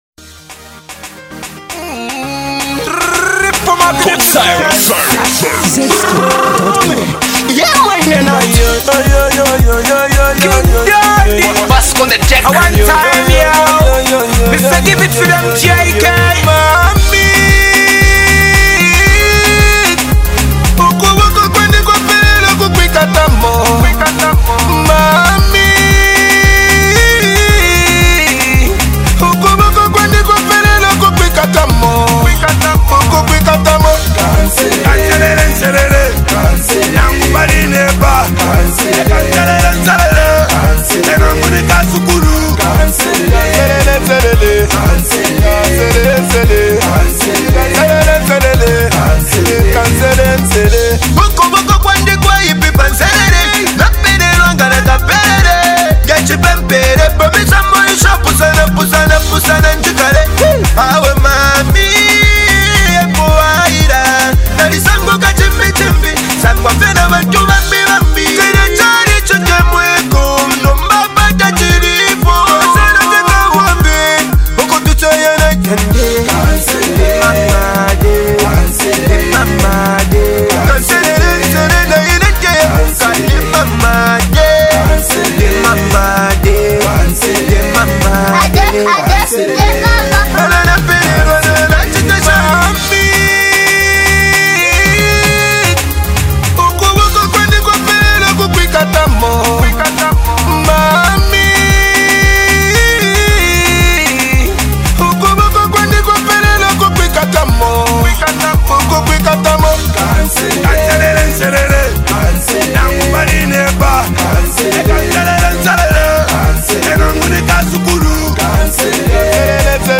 Old Zambian Music